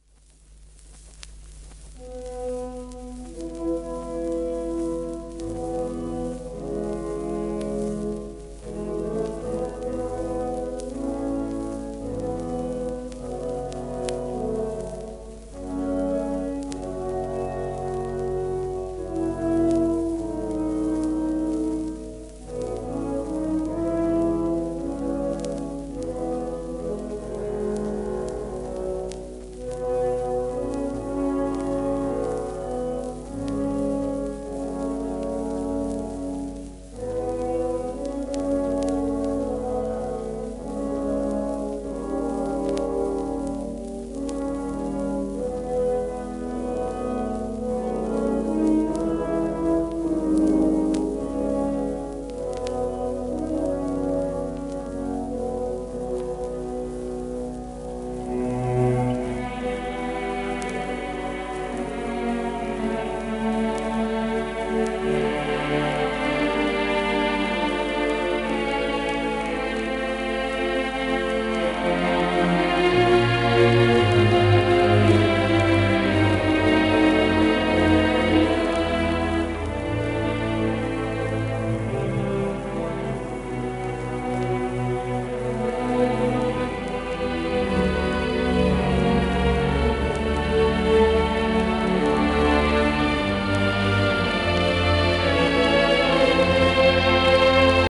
紙芯入りシェラック樹脂
録音は1932年